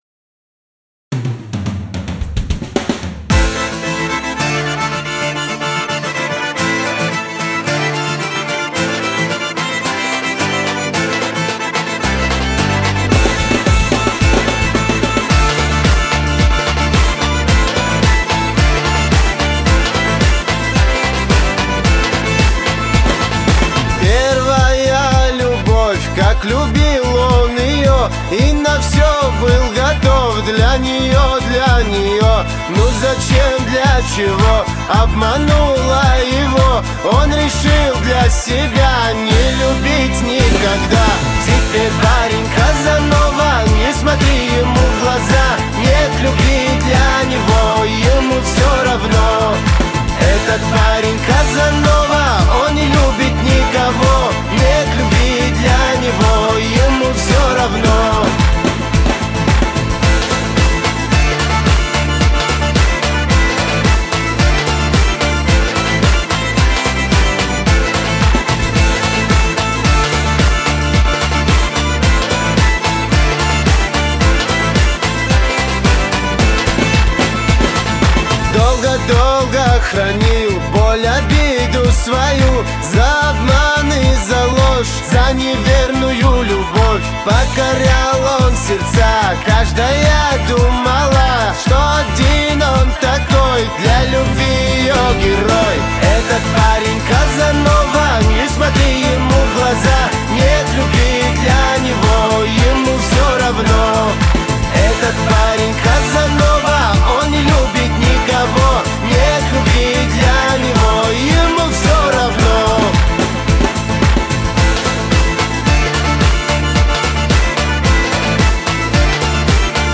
Категория: Восточная музыка » Кавказские песни